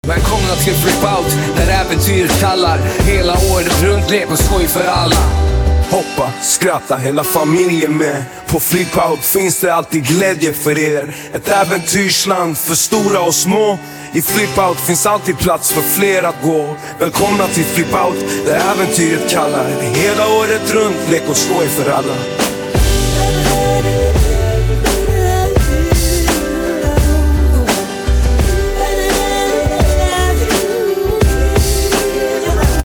• Radiojingle